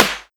SNARE49.wav